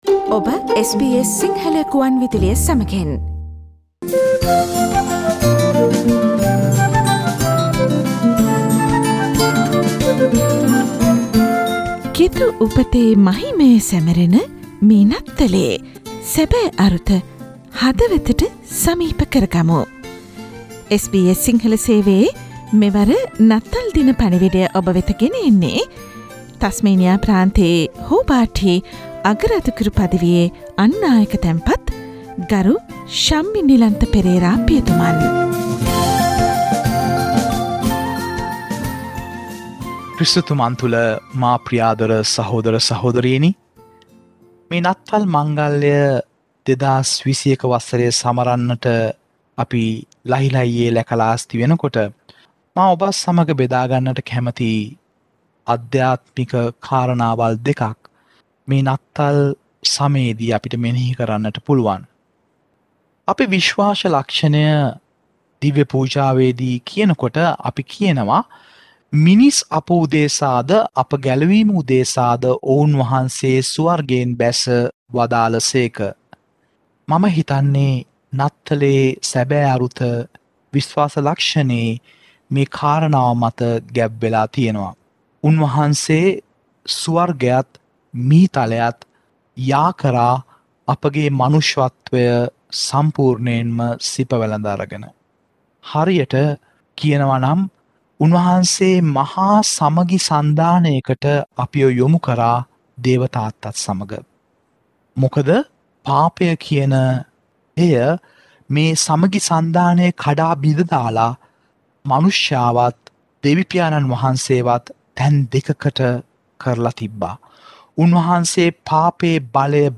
SBS Sinhala radio Christmas massage.